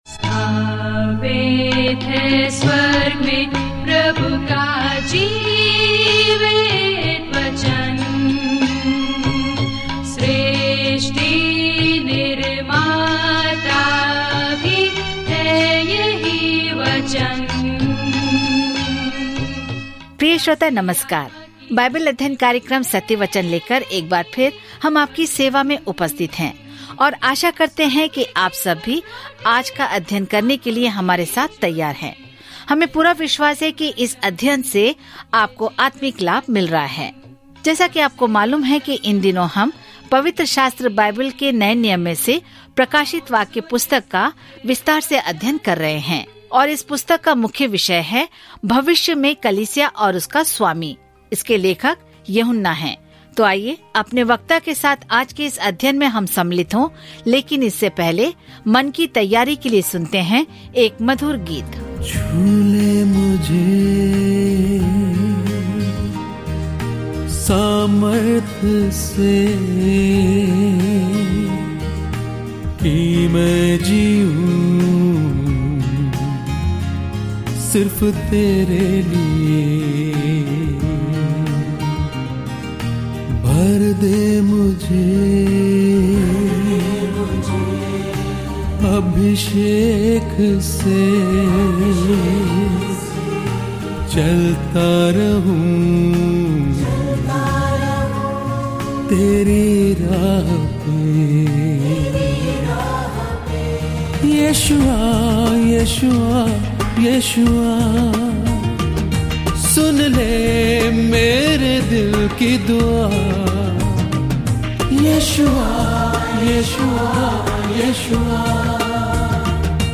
Scripture Revelation 13:2-8 Day 40 Start this Plan Day 42 About this Plan रहस्योद्घाटन इतिहास की व्यापक समयरेखा के अंत को इस तस्वीर के साथ दर्ज करता है कि अंततः बुराई से कैसे निपटा जाएगा और प्रभु यीशु मसीह सभी अधिकार, शक्ति, सुंदरता और महिमा में शासन करेंगे। रहस्योद्घाटन के माध्यम से दैनिक यात्रा करें क्योंकि आप ऑडियो अध्ययन सुनते हैं और भगवान के वचन से चुनिंदा छंद पढ़ते हैं।